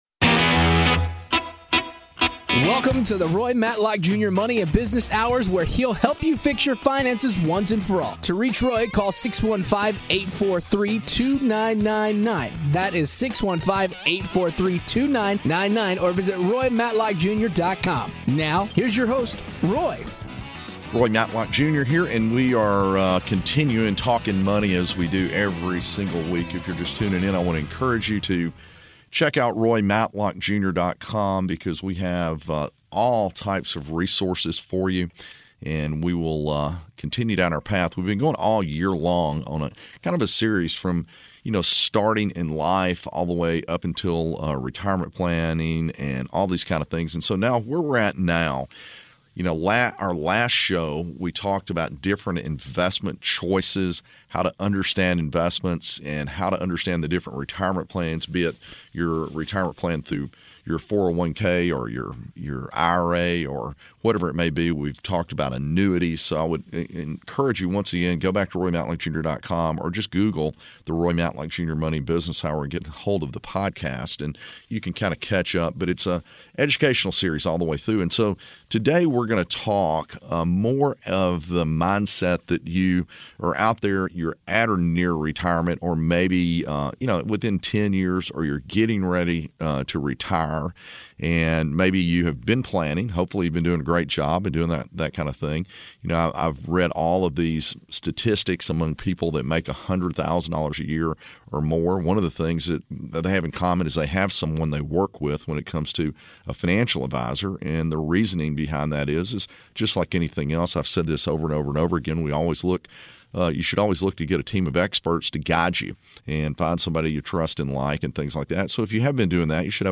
But have you considered the various income sources that may be attainable for you during your retirement years? In this episode of The Money and Business Hour radio show